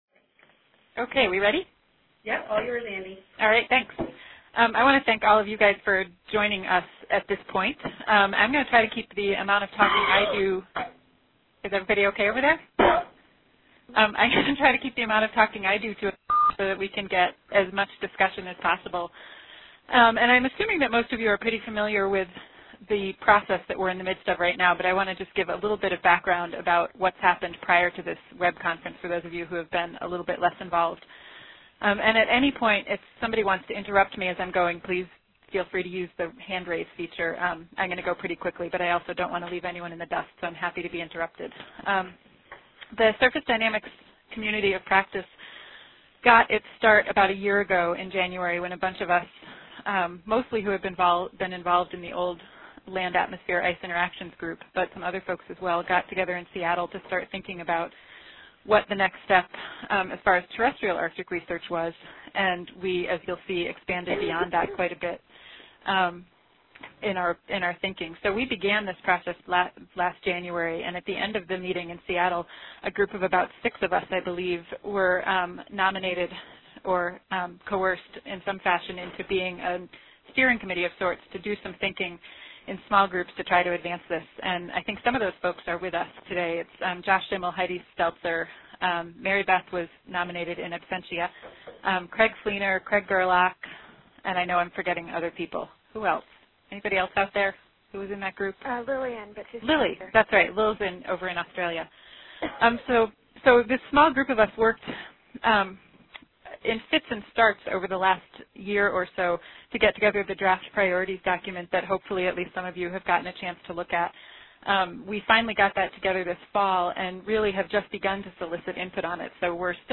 The Surface Dynamics Co-oP held this open eTown Meeting to solicit community input and discussion as they further develop and refine their science priorities.